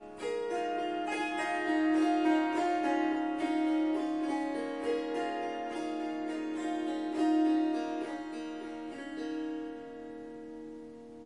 Swarmandal印度竖琴曲谱 " 竖琴的可爱曲谱
这个奇妙的乐器是Swarmandal和Tampura的结合。
它被调到C sharp，但我已经将第四个音符（F sharp）从音阶中删除了。
一些录音有一些环境噪音（鸟鸣，风铃）。